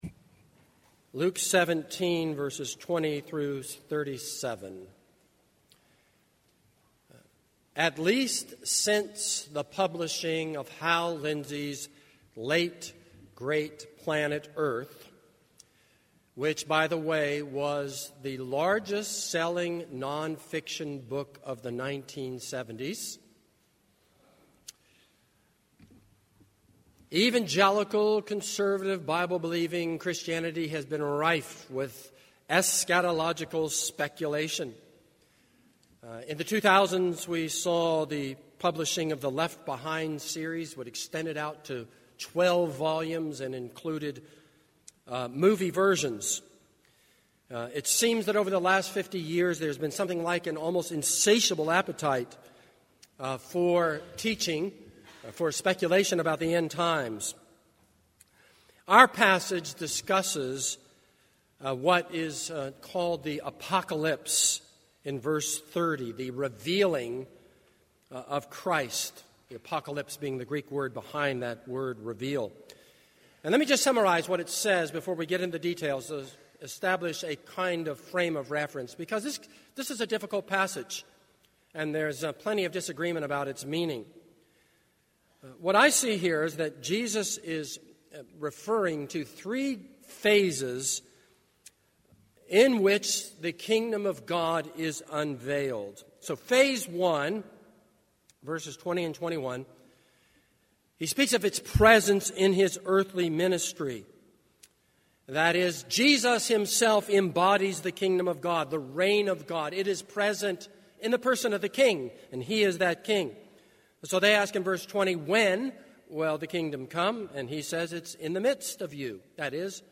This is a sermon on Luke 17:20-37.